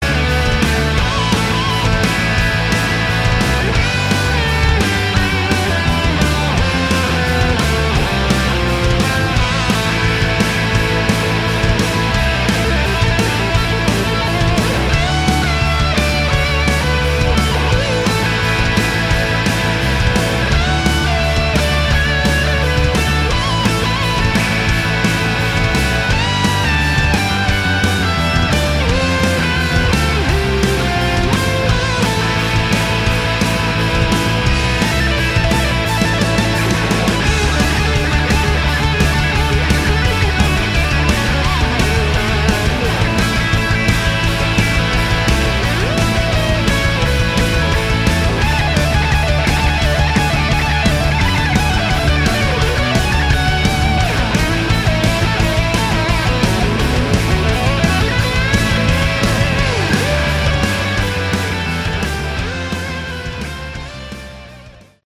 Category: Melodic Rock
drums
bass
guitars, keyboards
vocals